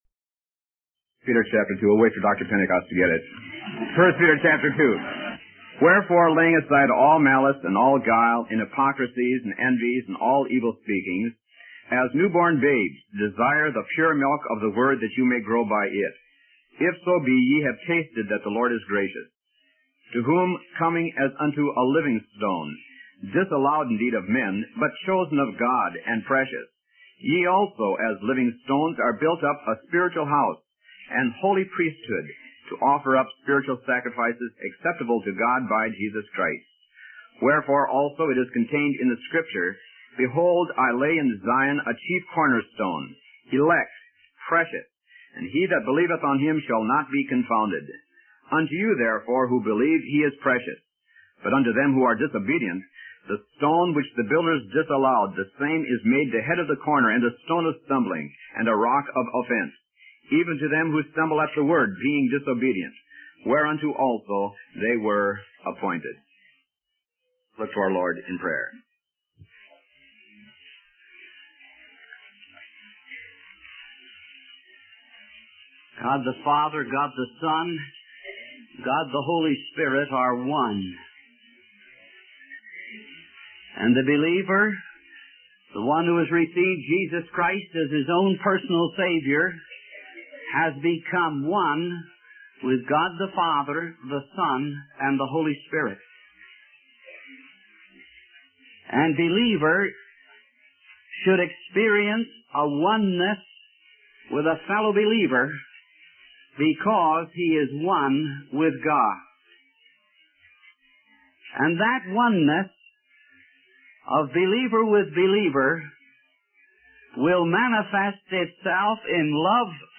In this sermon, the apostle discusses five things that believers should lay aside: malice, guile, hypocrisy, envy, and evil speaking.